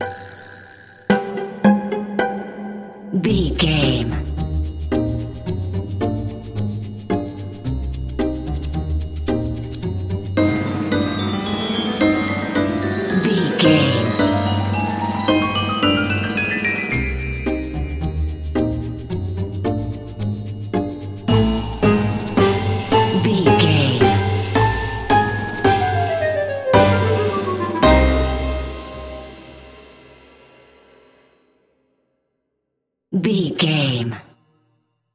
Aeolian/Minor
percussion
strings
silly
circus
goofy
comical
cheerful
perky
Light hearted
sneaking around
quirky